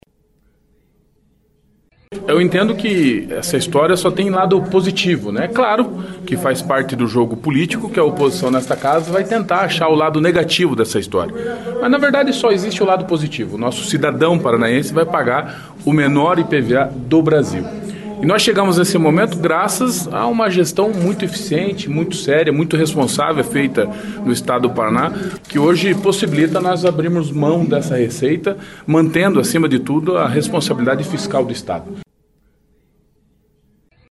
Mesmo sem a chegada oficial do projeto, deputados estaduais de oposição e situação comentaram sobre a redução de 45% durante sessão ordinária na tarde desta segunda-feira (25).